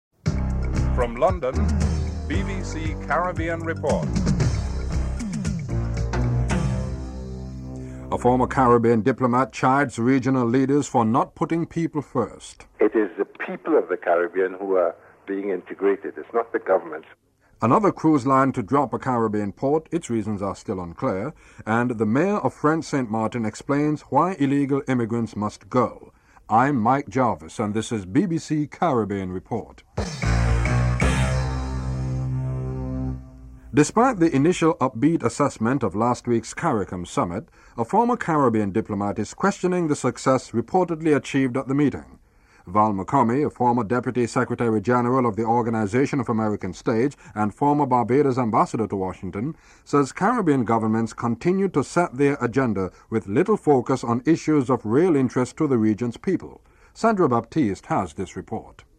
1. Headlines (00:00-00:40)
Interview with Carlyle Dunkley, Minister of Industry and Tourism, Jamaica (04:29-06:47)